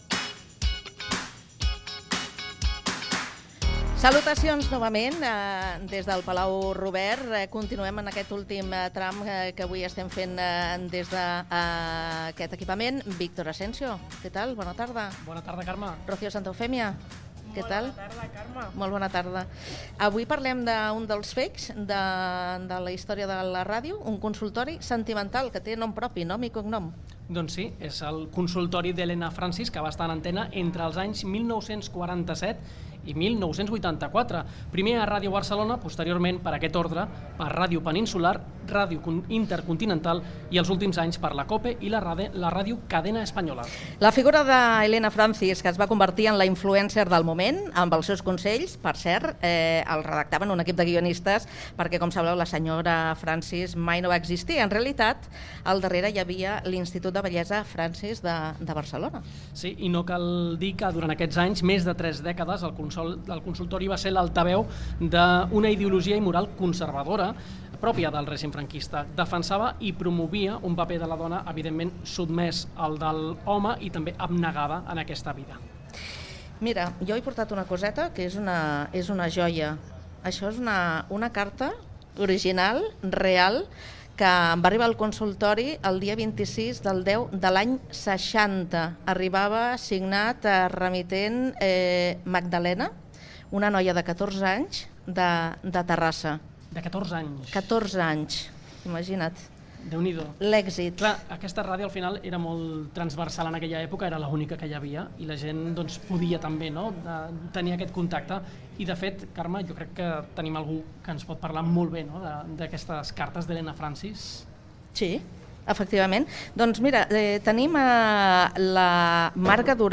Espai fet des del Palau Robert.
Entreteniment